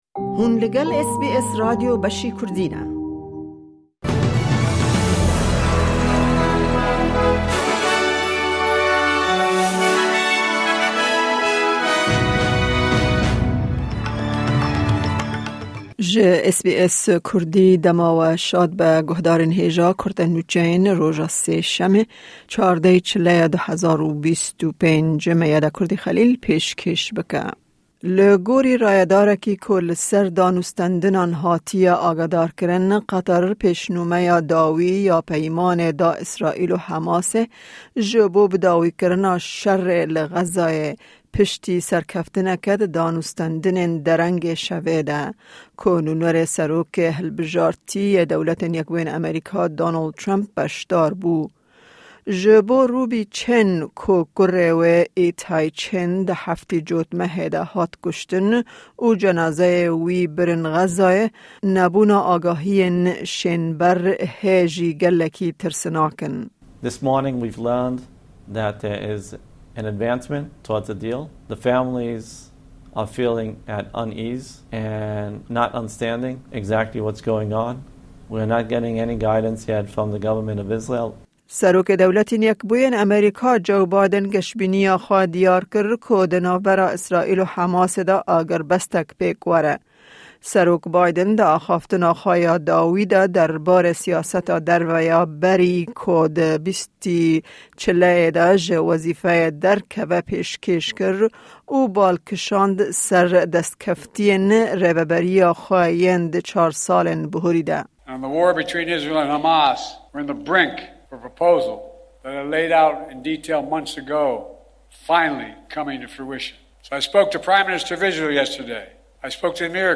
Kurte Nûçeyên roja Sêşemê, 14î Çileya 2025